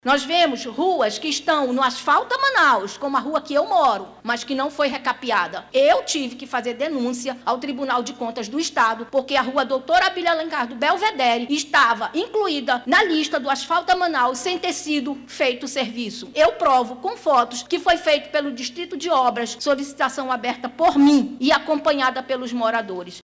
A Câmara Municipal de Manaus – CMM realizou nesta quarta-feira 17/04, uma Tribuna Popular para receber as demandas dos moradores da zona Centro-Oeste da capital amazonense.